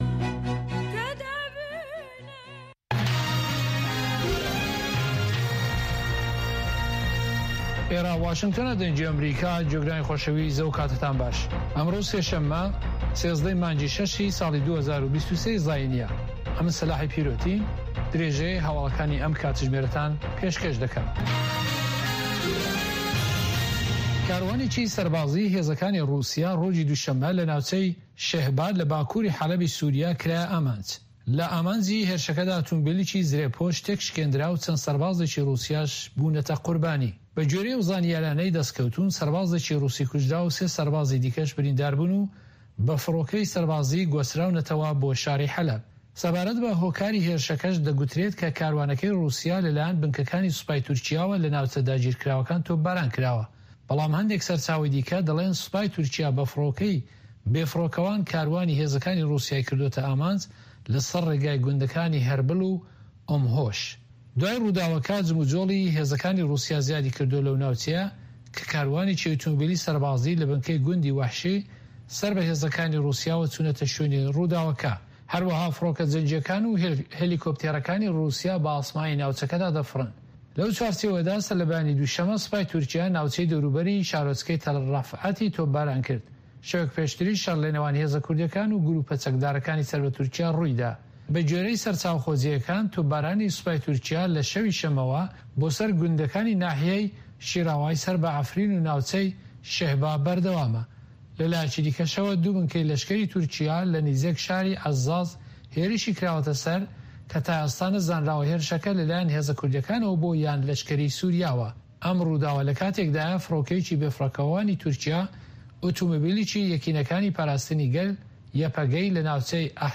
Nûçeyên Cîhanê 2